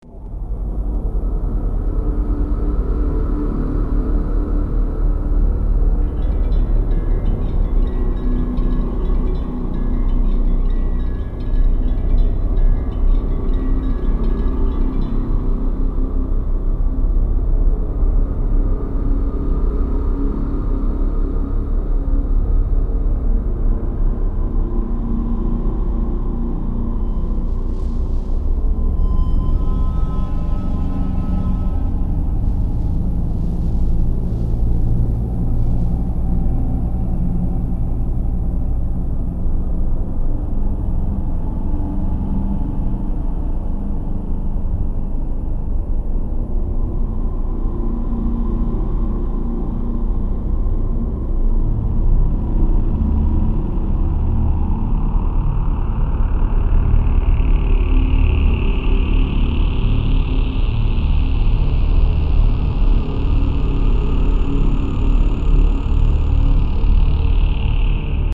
Perfect for ambient, anxious, creepy.